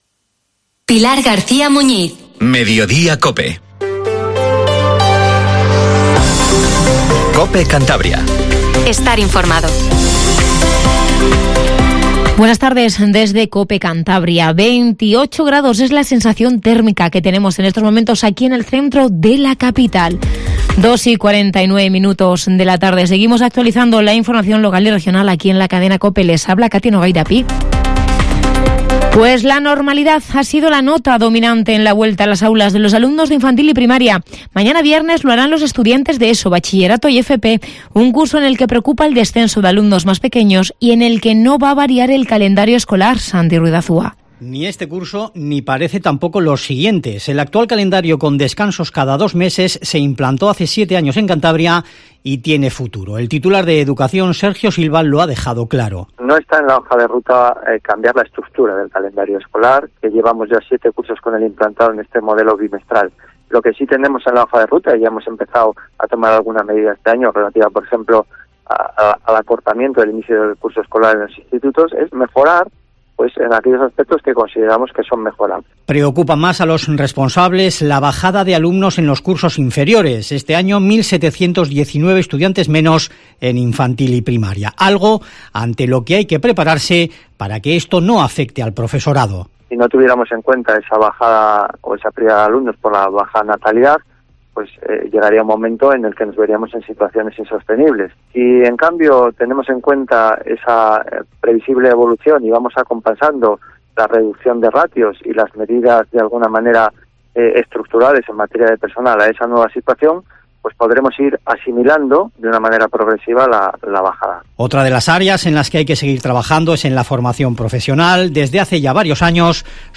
Informativo MEDIODIA COPE CANTABRIA 14:48